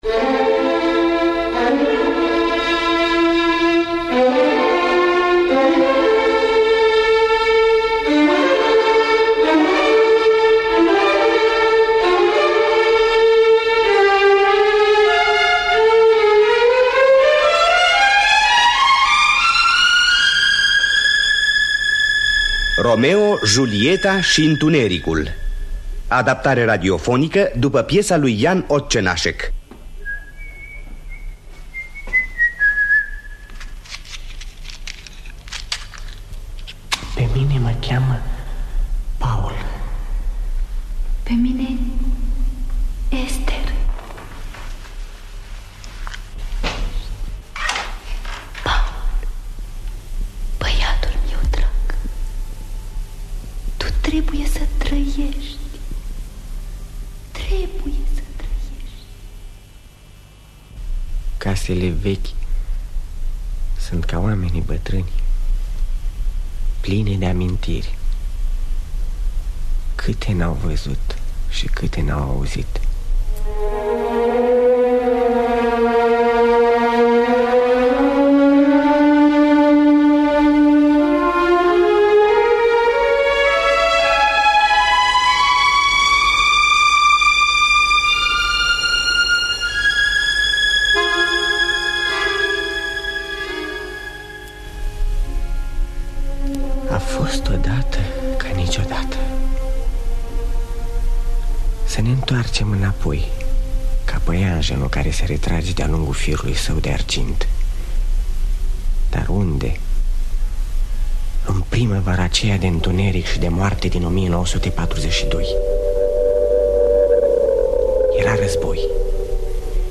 Romeo, Julieta și întunericul de Jan Otčenášek – Teatru Radiofonic Online